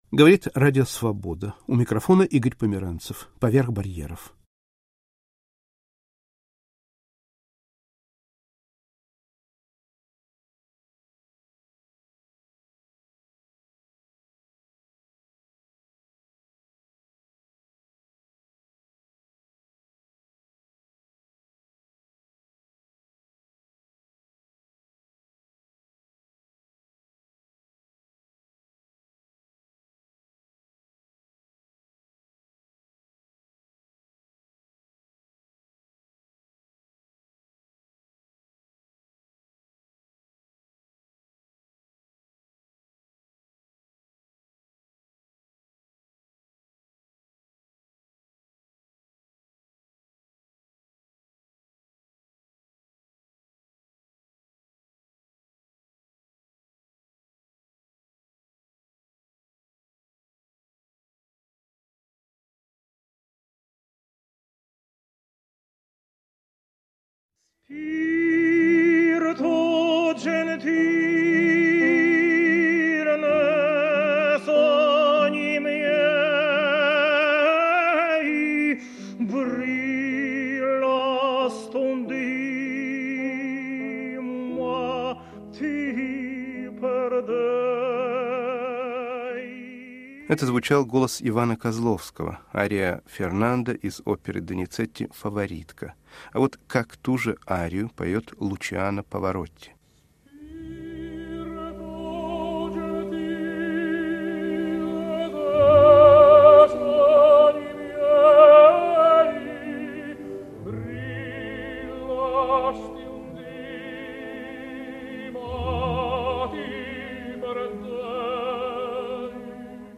Звучат голоса Ирины Архиповой, Зураба Соткилавы, Сергея Лейферкуса